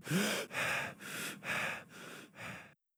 Breath End.wav